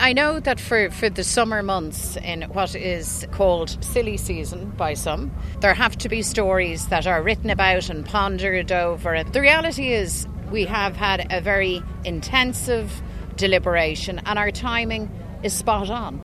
Speaking at the National Ploughing Championships today, the party leader refused to reveal any details of the chosen candidate, with an official announcement due on Saturday.